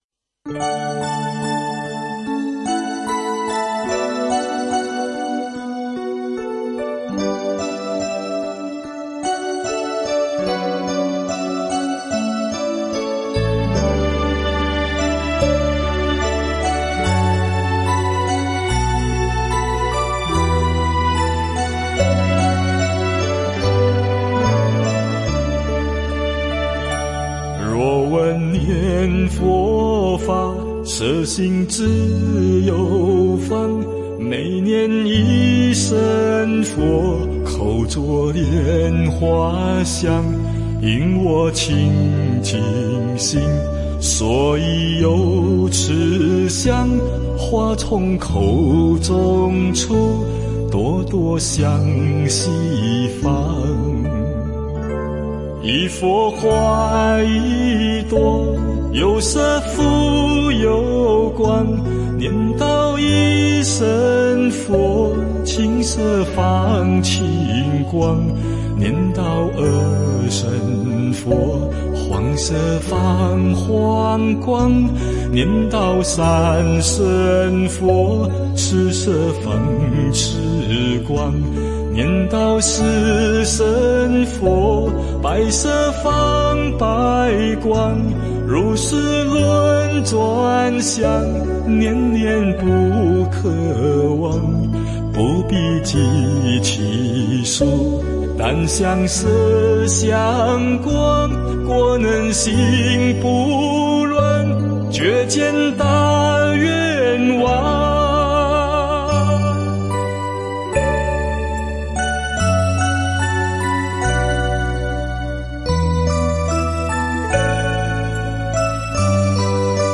佛音 诵经 佛教音乐 返回列表 上一篇： 不动佛心咒 下一篇： Drolmai Yang 相关文章 楞严咒